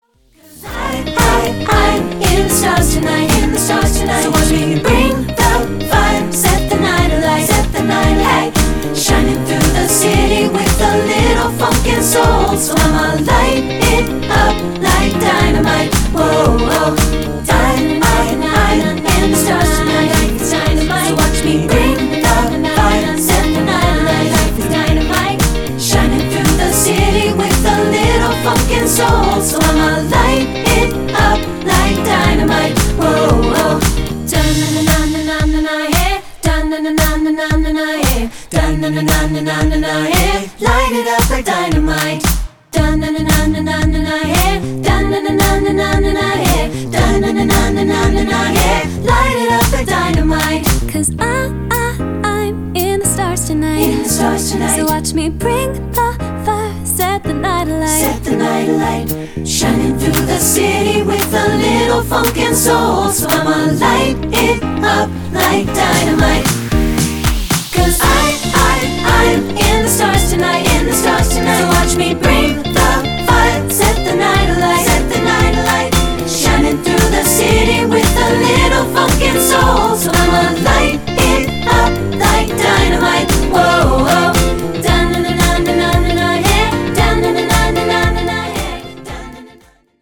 Choral Recent Pop Hits
keep the retro-Disco sound
Voicing
SATB